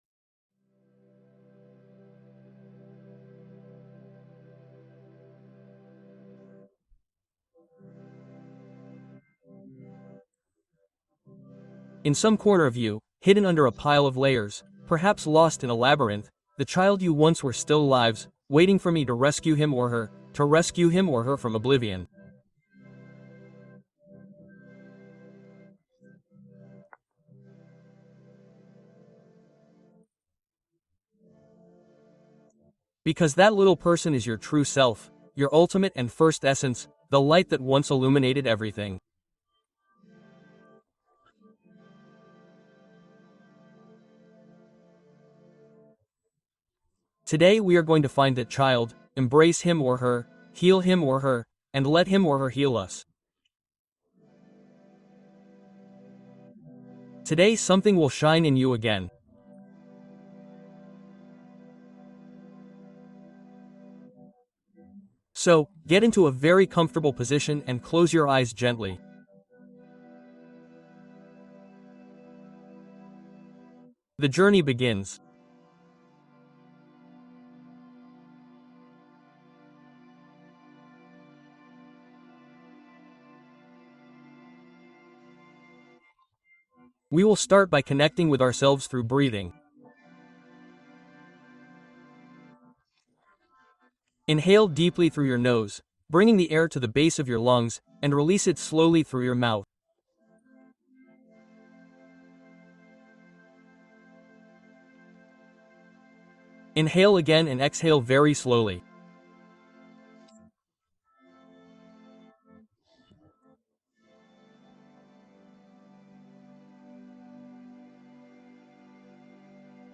Meditación para Sanar al Niño Interior Antes de Dormir